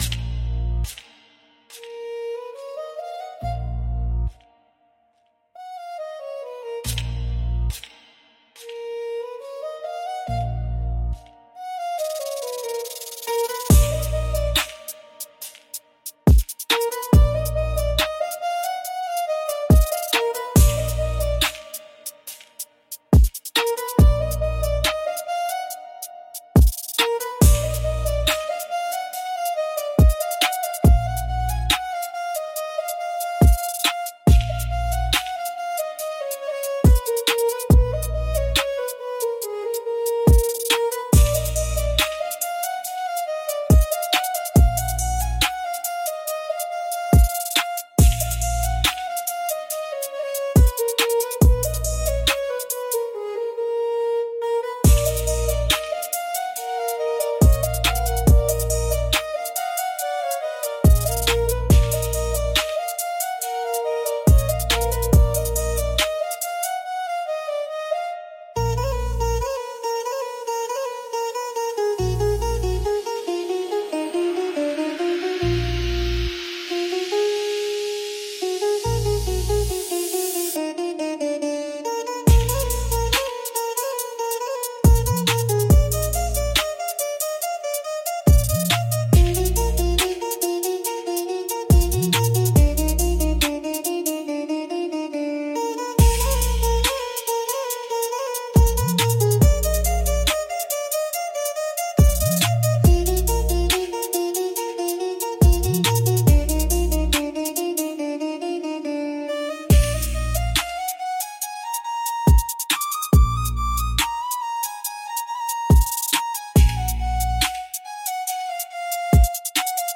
B♭ Minor -140 BPM
Drill
Trap